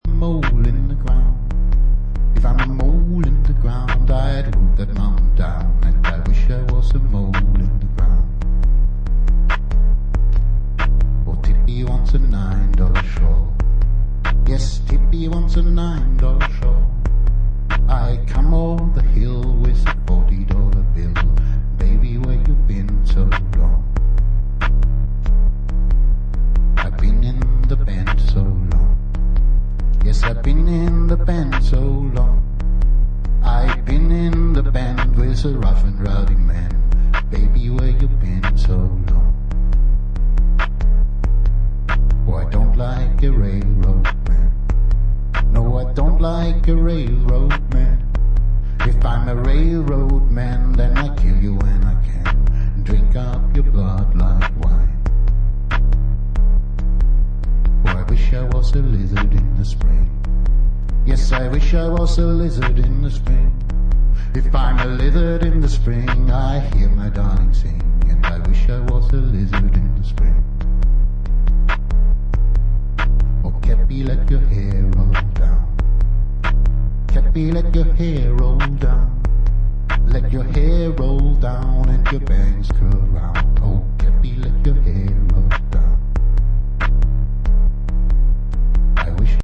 The impulsive live performance
elaborated beat structures and sound landscapes